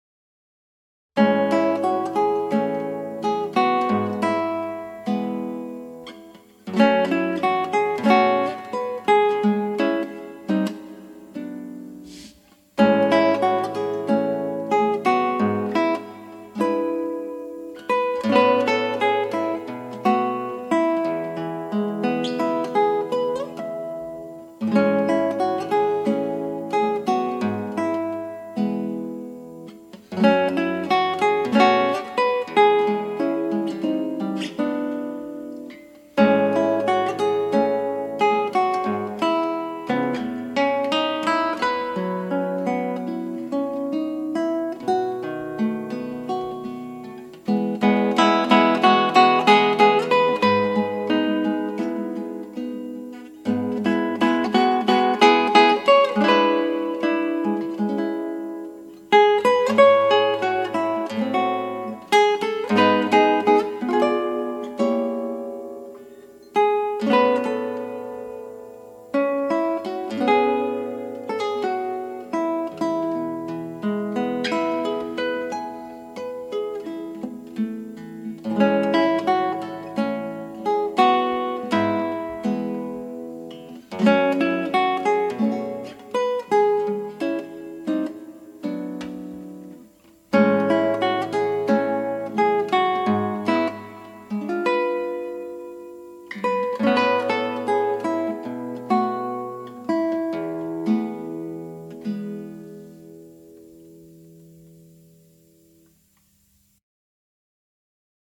ギターの自演をストリーミングで提供